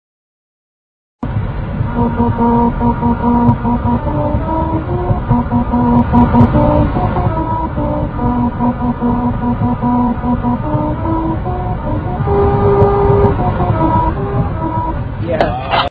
Once you get there you have to drive in the fast lane at 55 MPH, turn down your radio and, as your tires drive over the grooves in the street, a part of Rossini's "The William Tell Overture" is played.
25_Musical_road.mp3